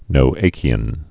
(nō-ākē-ən) also No·ach·ic (-ăkĭk) or No·ach·i·cal (-ĭ-kəl)